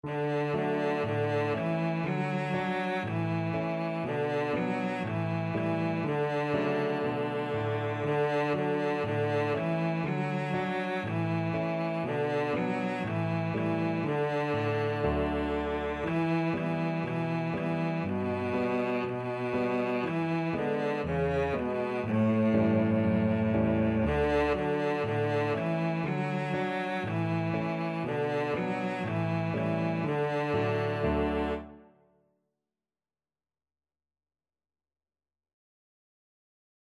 Cello
Traditional Music of unknown author.
4/4 (View more 4/4 Music)
D major (Sounding Pitch) (View more D major Music for Cello )
Allegro (View more music marked Allegro)